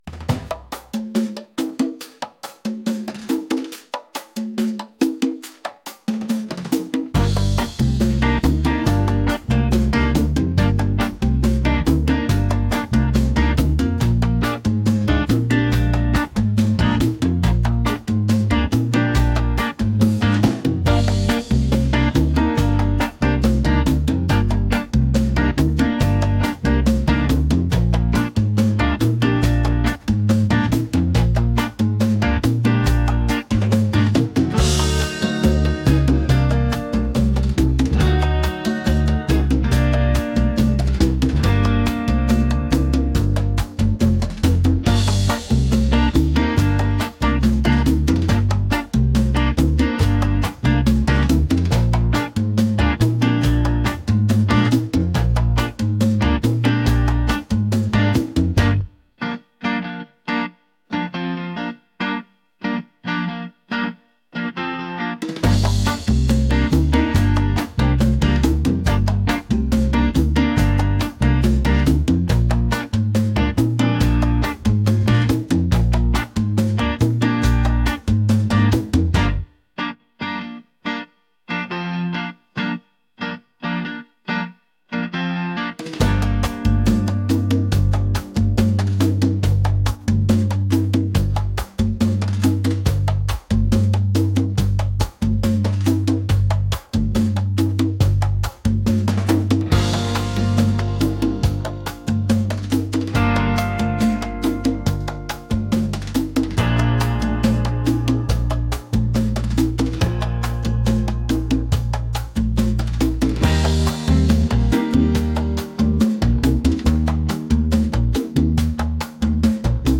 energetic | latin